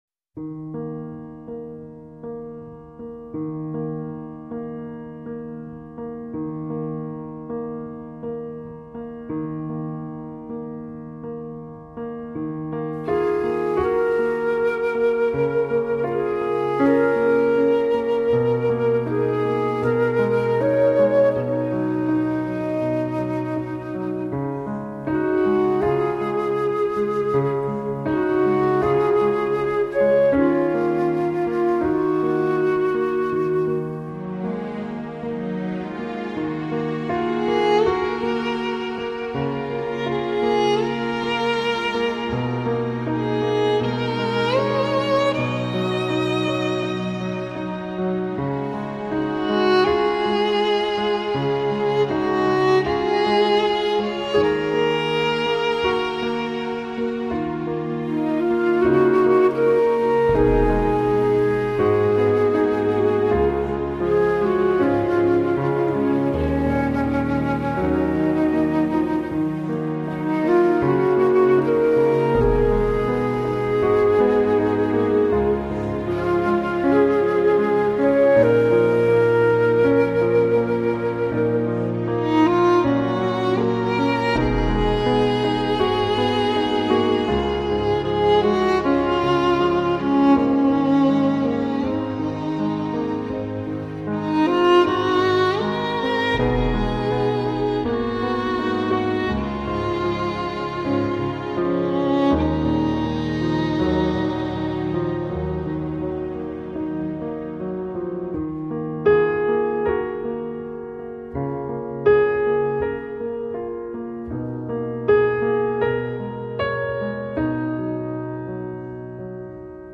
柔美、连贯而又自然不过
淡淡清爽的音乐，一听就喜欢，谢谢分享！